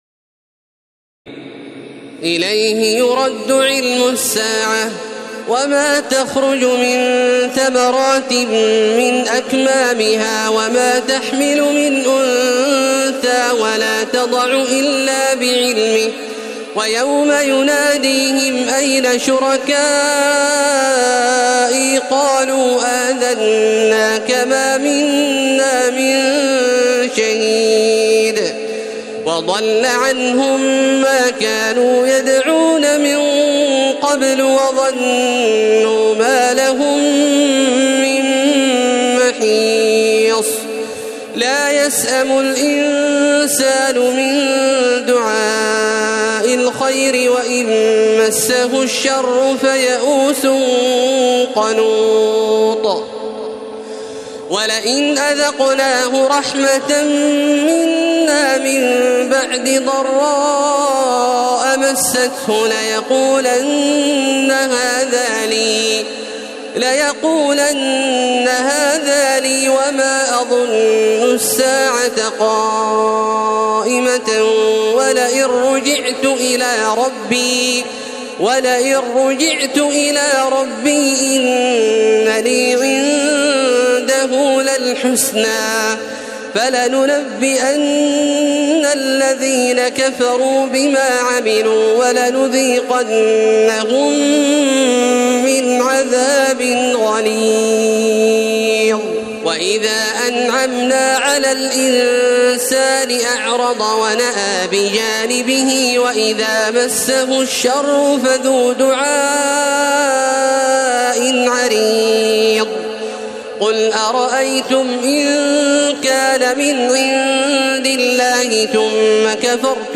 تراويح ليلة 24 رمضان 1432هـ من سور فصلت (47-54) و الشورى و الزخرف (1-25) Taraweeh 24 st night Ramadan 1432H from Surah Fussilat and Ash-Shura and Az-Zukhruf > تراويح الحرم المكي عام 1432 🕋 > التراويح - تلاوات الحرمين